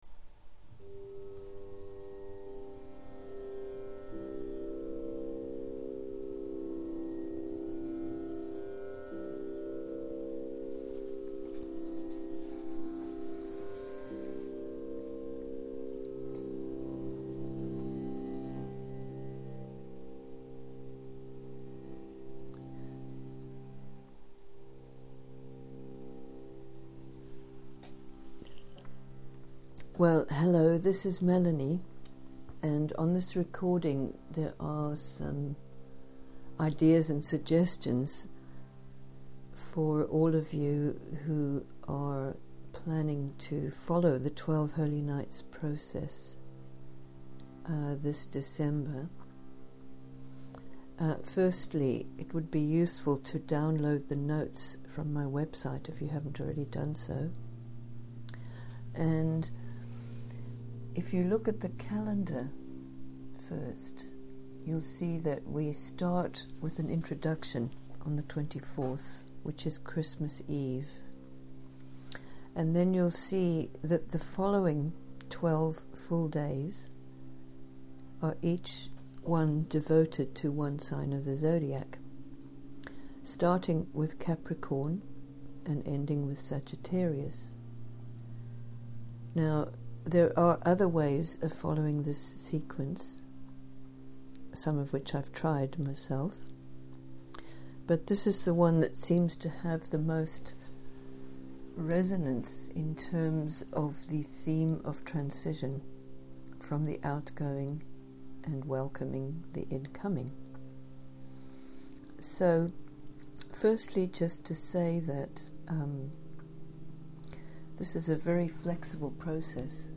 Secondly, download the free MP3 on which some suggestions are offered. There are some meditative sounds at the end of the talking, and these also run quietly in the background during the first part of the recording.